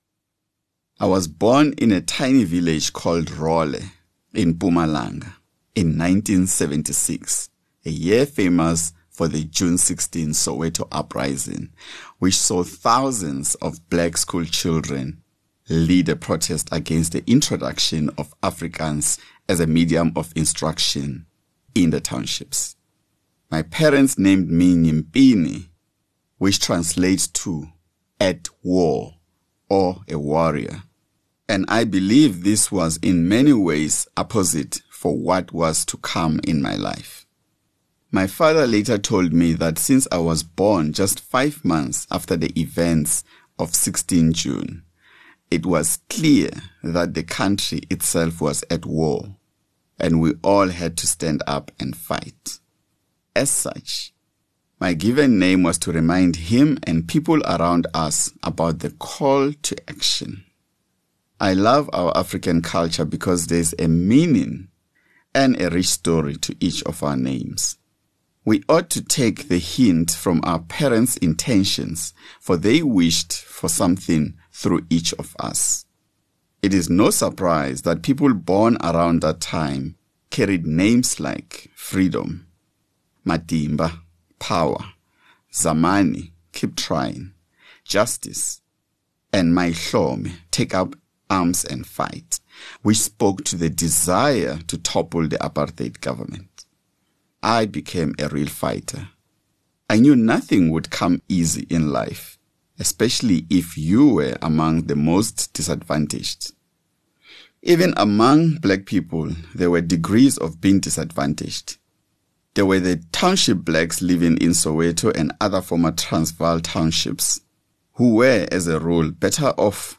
Audiobook Samples from Solid Gold